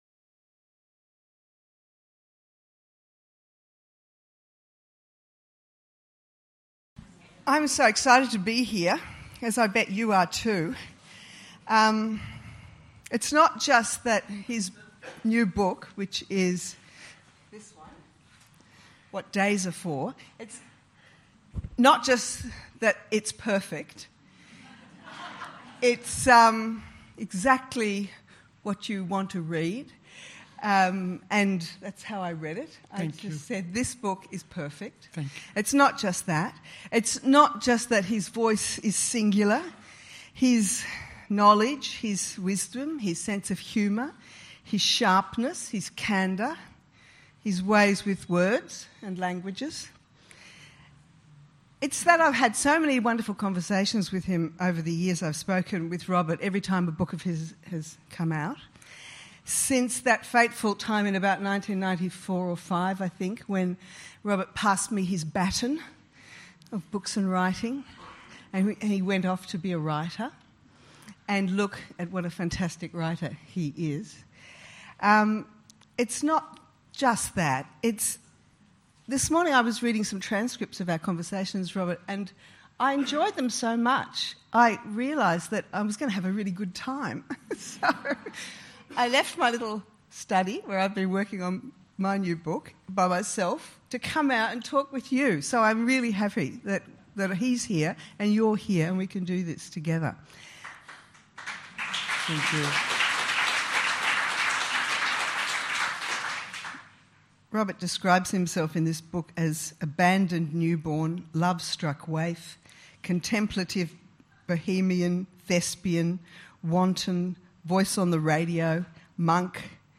Opening with a reading from his book, Dessaix traces a path through travel, love, coincidence, intimacy, language, youth and death, in conversation with Ramona Koval.